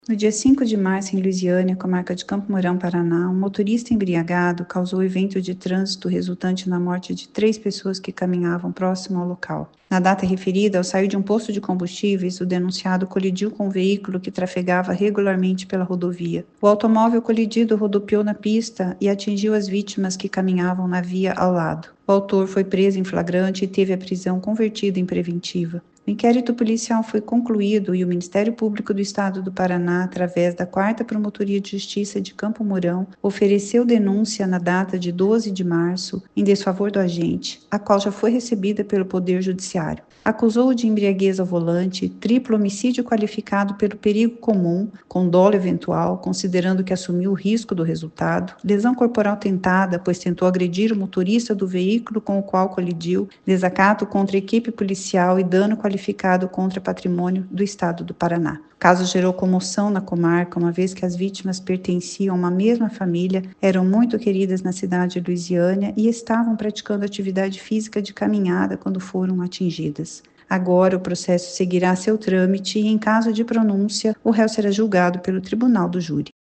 Ouça o que diz a promotora de Justiça Lígia Camargo Grasso.